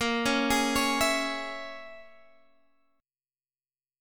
A#m7b5 chord